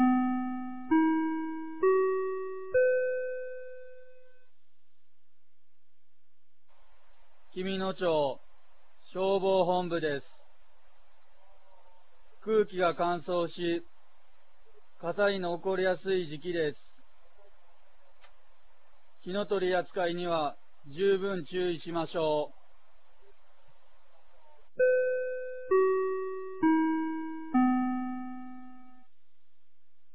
2023年11月11日 16時00分に、紀美野町より全地区へ放送がありました。